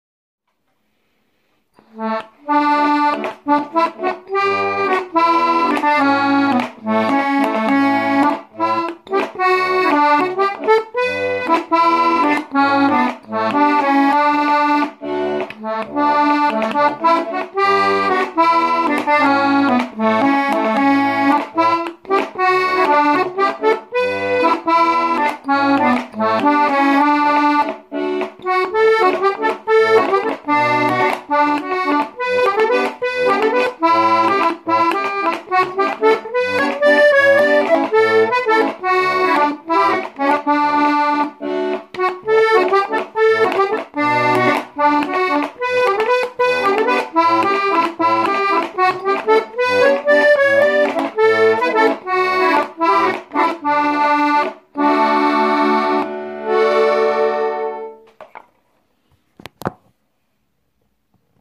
Habe heute Abend mal mit meiner Marca A/D das "Fanny Power" von O'Carolan aus dem K. Tune Book aufgenommen. Habe mich dabei an Verzierungen versucht.
Die Bassknöpfe klappern noch lauter als bei der Erica.